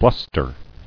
[blus·ter]